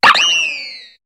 Cri de Shaofouine dans Pokémon HOME.